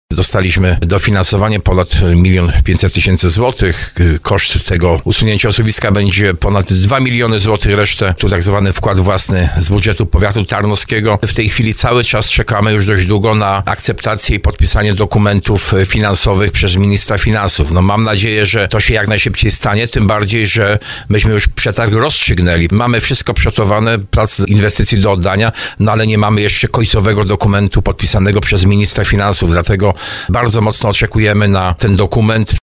Mówił o tym w programie Słowo za Słowo starosta tarnowski Jacek Hudyma.